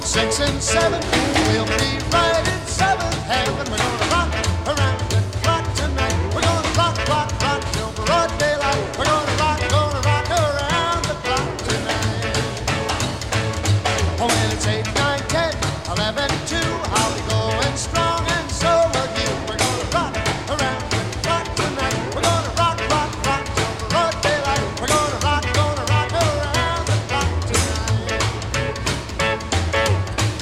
"templateExpression" => "Rock, pionniers"